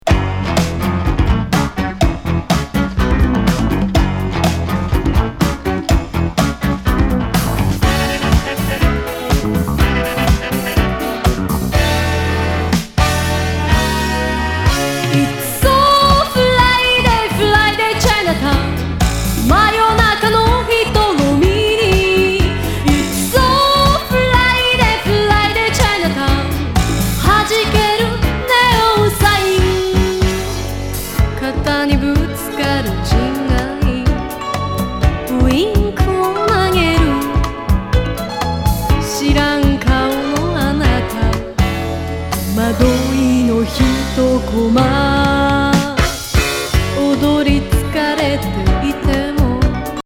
アップリフティン・アーバン・ディスコ歌謡！